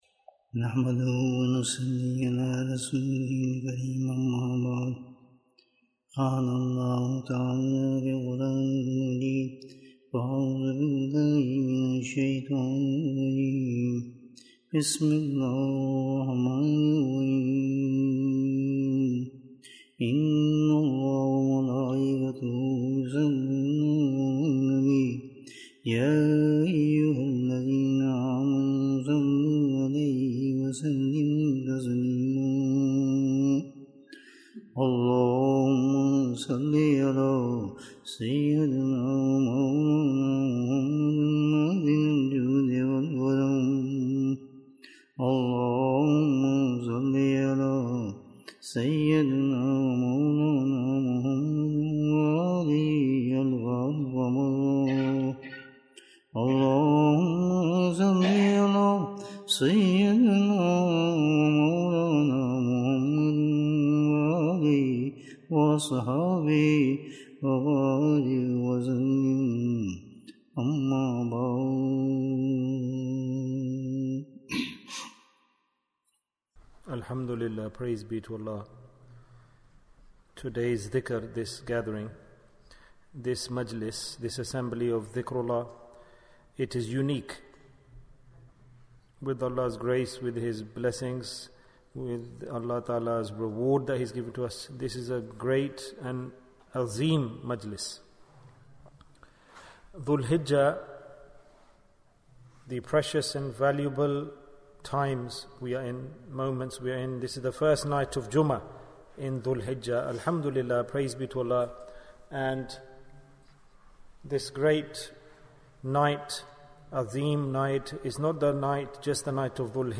Special Ibaadah for the Ten Days of Dhul Hijjah Bayan, 40 minutes30th June, 2022